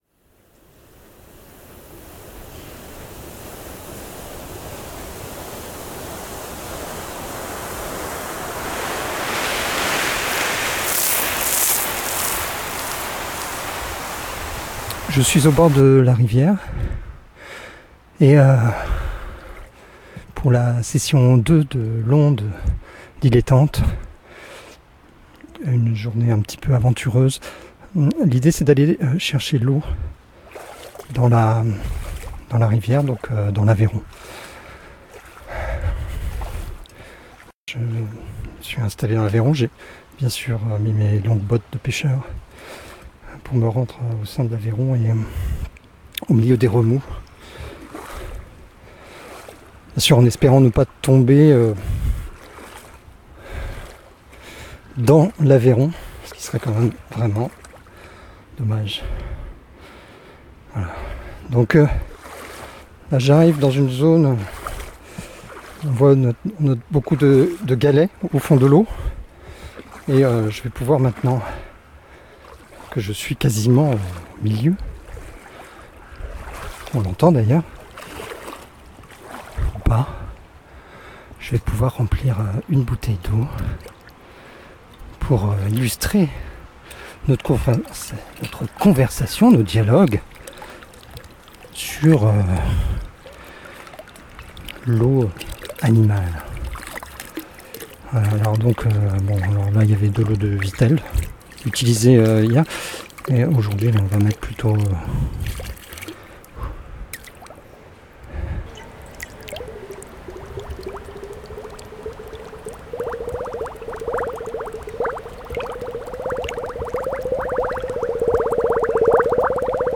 Dégustations d’eau pendant le festival « La Cour et l’eau » au centre d’art et de design “La cuisine” à Négrepelisse.